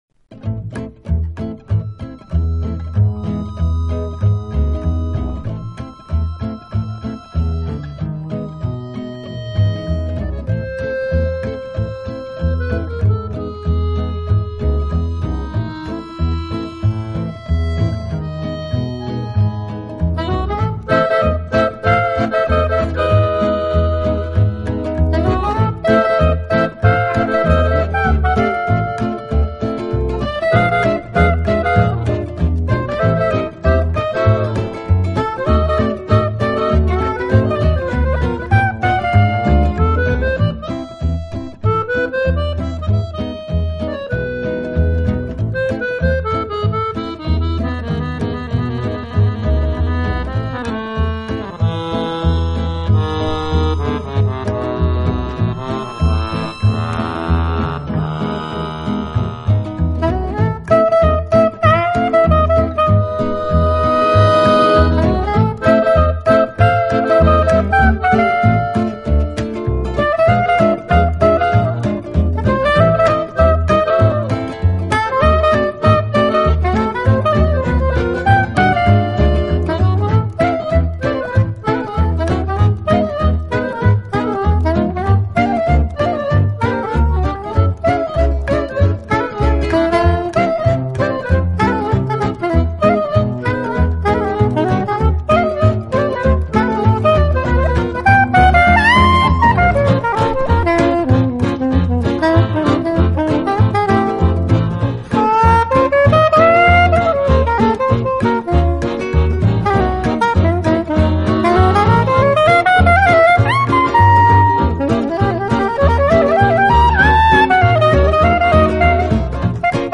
Genre: Jazz / World Music
它，配上超重低音的牛筋、浪漫的手风琴和感性的萨斯风，每张专辑均德国顶级录音室录音。
在该专辑中，他们尝试将意大利探戈与充满诗意的歌曲相融合。
(萨克思, 单簧管, 曼陀铃, 电颤琴及其它乐器) 表演感情丰富，并擅长即兴演奏
(吉他及其它乐器)，娴熟的弗拉明戈演奏技巧
(手风琴，钢琴及其它乐器) 忧郁的嗓音充满魔力，经常让他的乐器只发出呼吸般的声音。
(贝司, 打击乐器及其它乐器) 节奏感极佳，他的贝司时常被用作打击乐器。